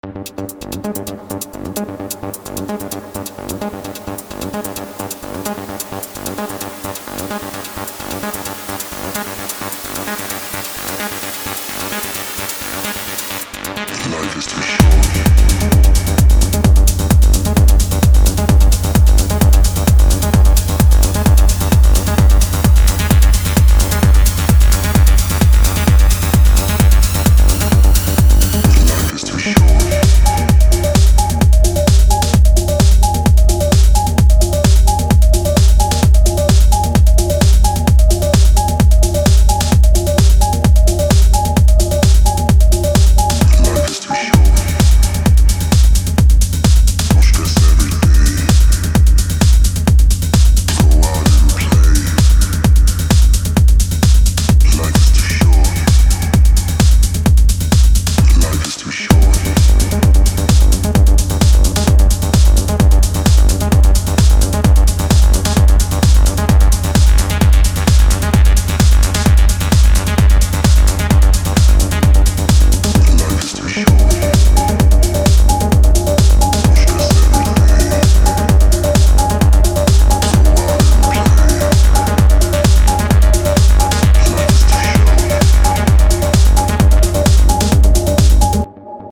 Демка на оценку